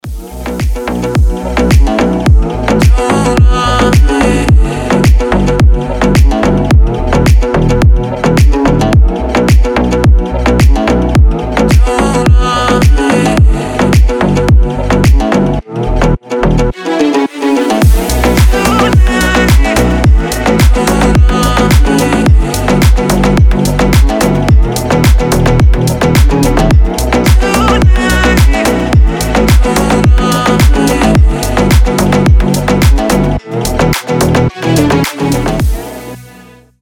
• Качество: 320, Stereo
deep house
релакс
восточные
расслабляющие
Приятная расслабляющая музыка с восточным аккордом